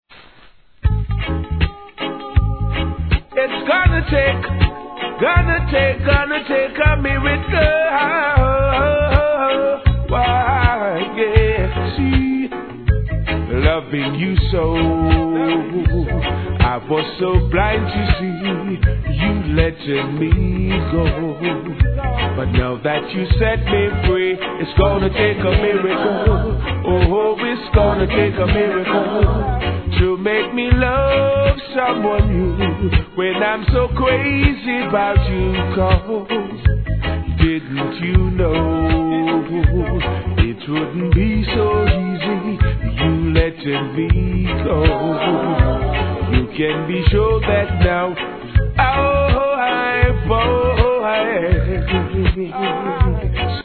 REGGAE
SOUL名曲カヴァー